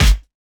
Retro Snr 1.wav